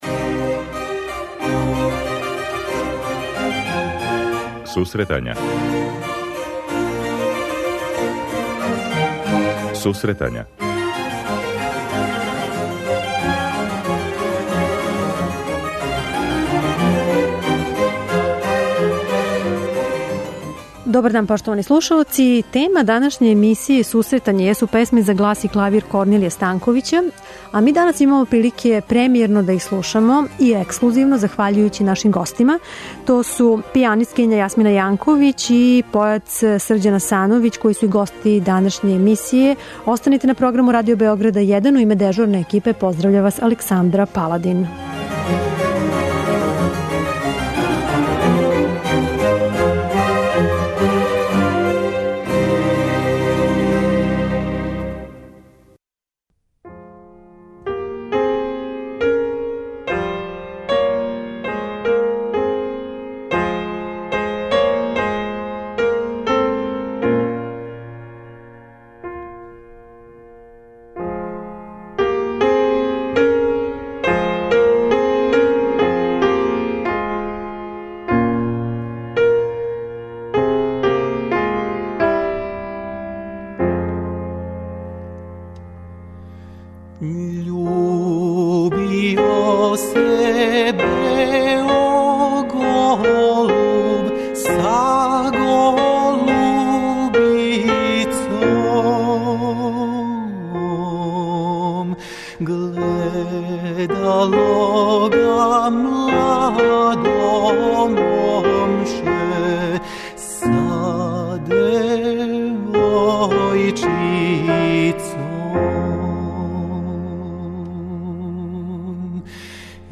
Ови уметници недавно су снимили опус музике Корнелија Станковића за глас и клавир. У данашњој емисији ови снимци ће бити ексклузивно емитовани.